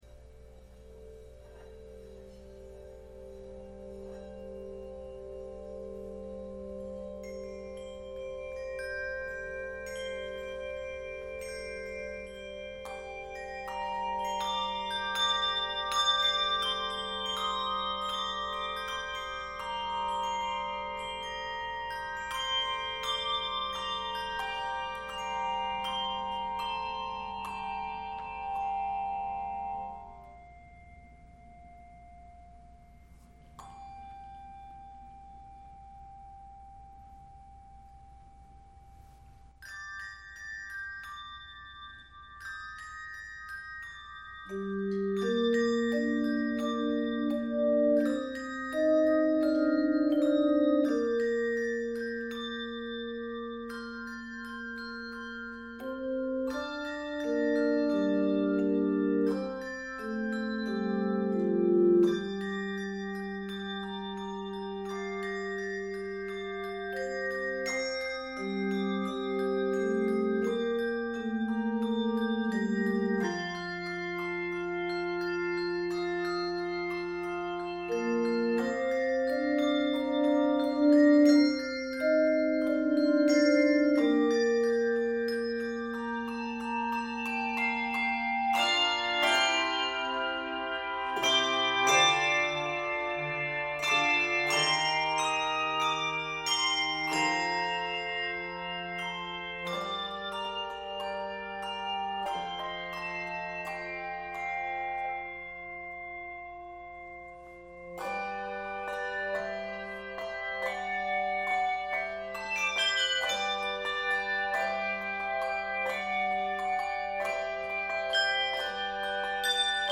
Keys of g minor and G Major.